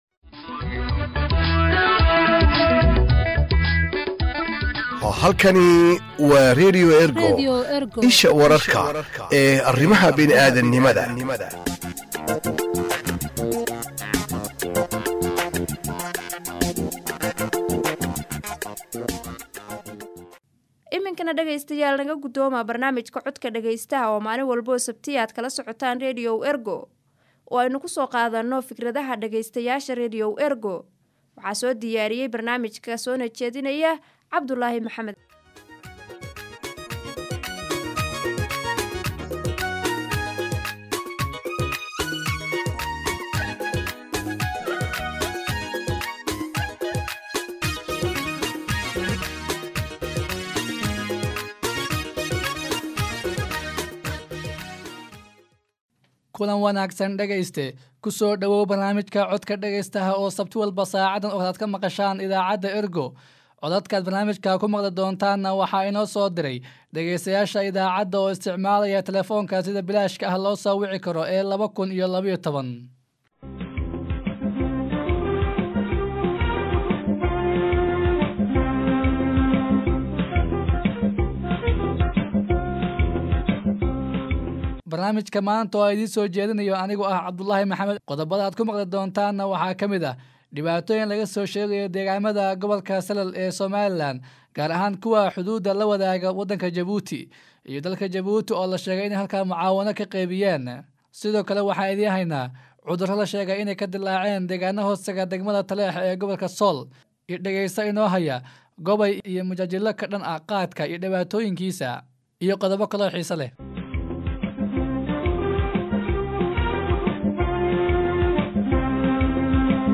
Haddii aad joogto Soomaaliya oo aad jeceshahay in aad barnaamijka ka qeyb qaadato si bilaash ah ayaad farriintaada oo cod ah noogu soo duubi kartaa.